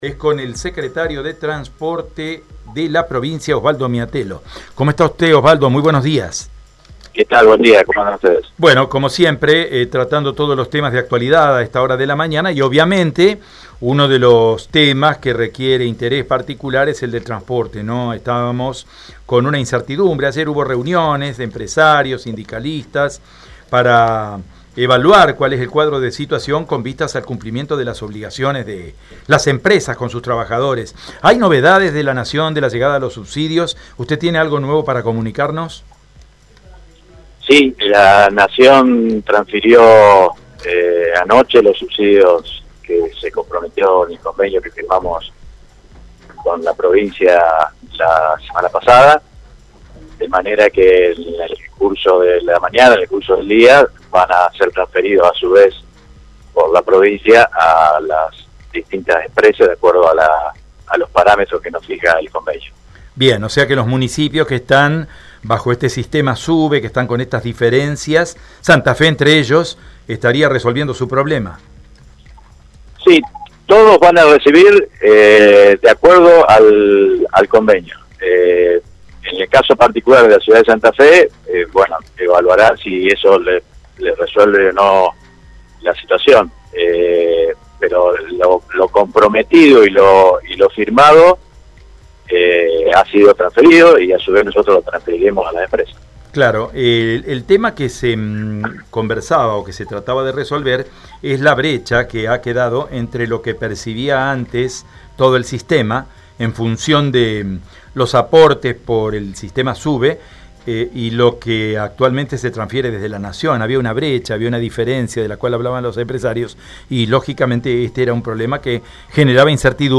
Osvaldo Miatello en Radio EME:
OSVALDO-MIATELLO-TRASPORTE.mp3